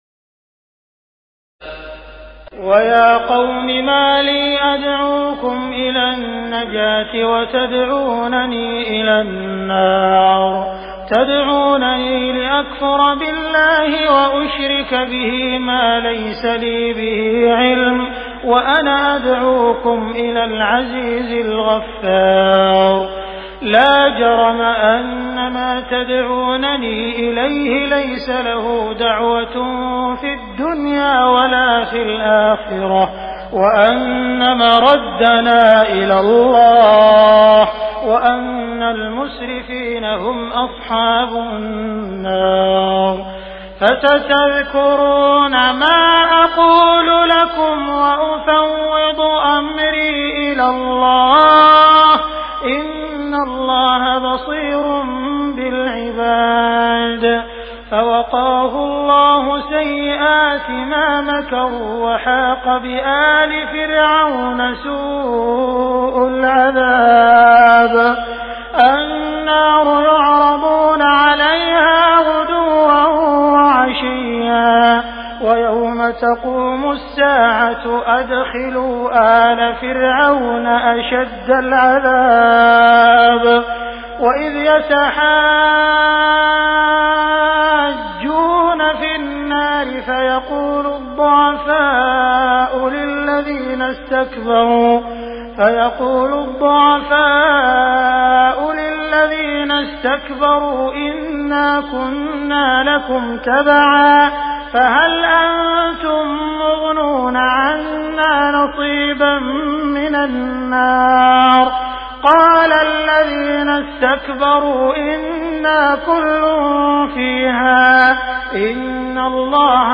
تراويح ليلة 23 رمضان 1418هـ من سور غافر (41-85) و فصلت (1-44) Taraweeh 23 st night Ramadan 1418H from Surah Ghaafir and Fussilat > تراويح الحرم المكي عام 1418 🕋 > التراويح - تلاوات الحرمين